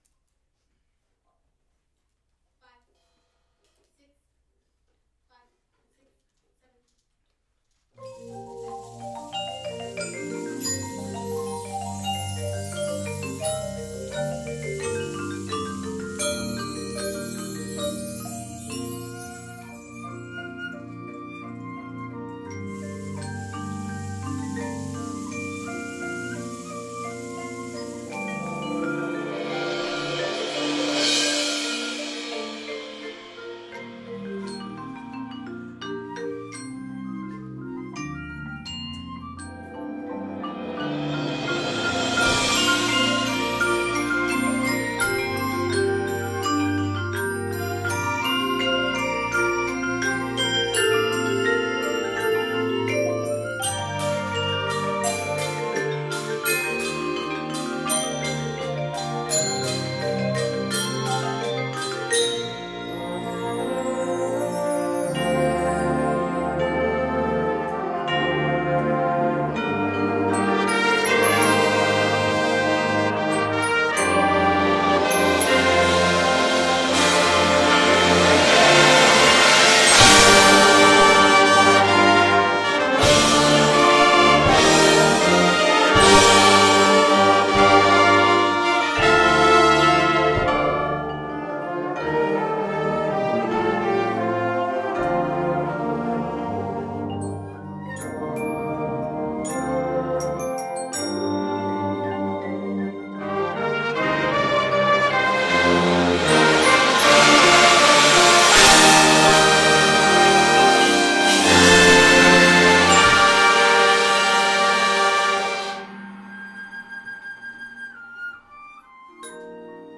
Ballad:
Ballad_Sept._29.mp3